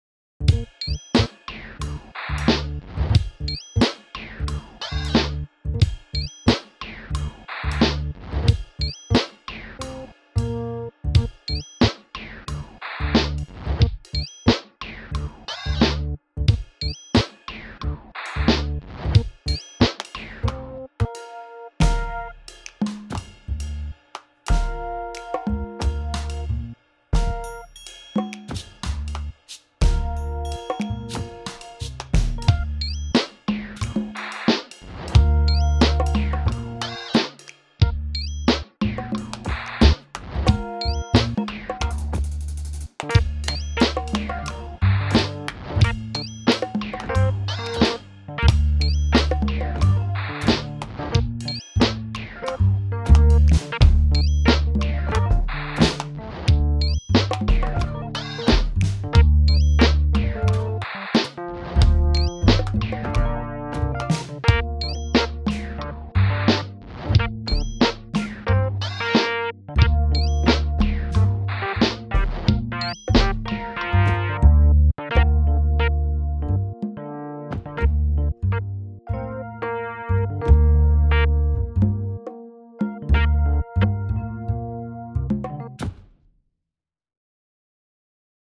instrumental instrument bass drums keys
90 BPM
Catchy cool glitchy fun!